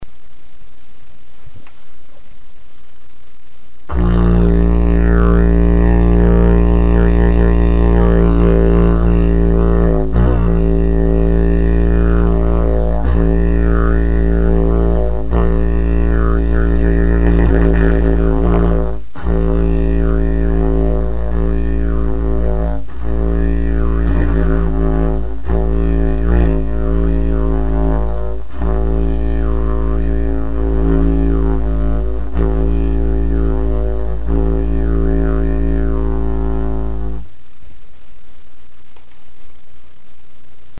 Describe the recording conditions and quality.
Click Here Granted its about the worst possible recording setup you could ever ask for, but since I am lacking pictures this is what you get.